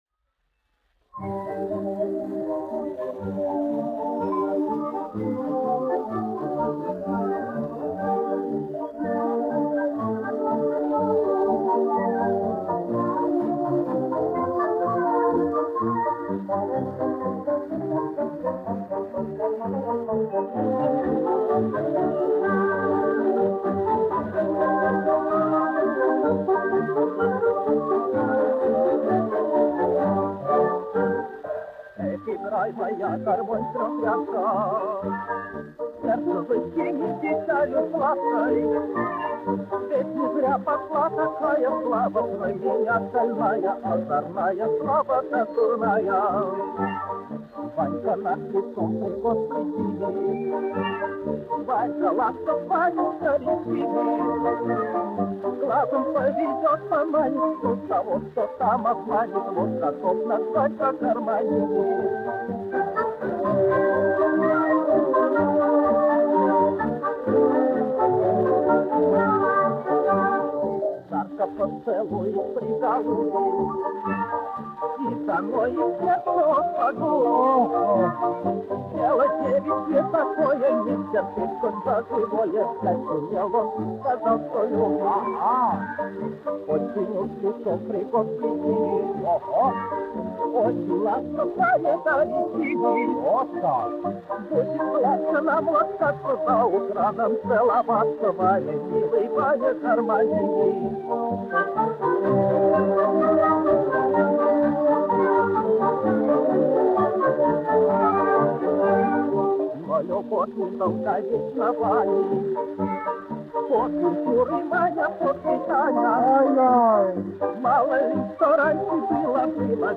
1 skpl. : analogs, 78 apgr/min, mono ; 25 cm
Populārā mūzika
Fokstroti
Skaņuplate